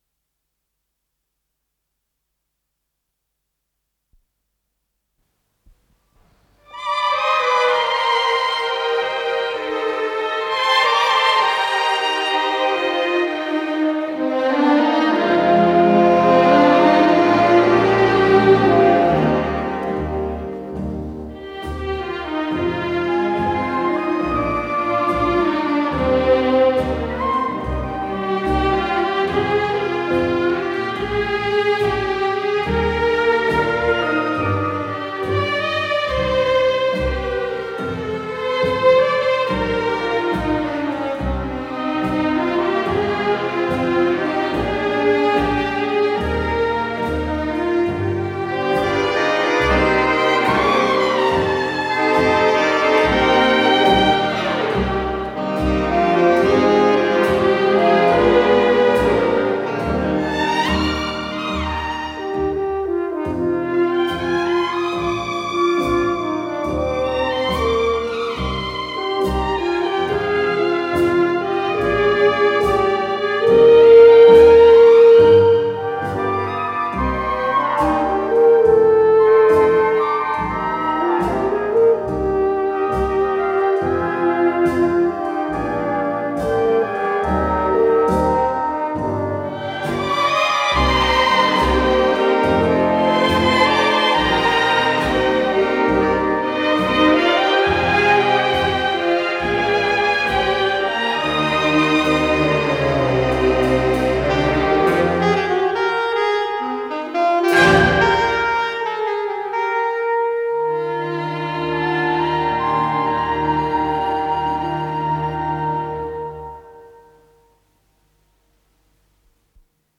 с профессиональной магнитной ленты
оркестровый вариант, ми бемоль мажор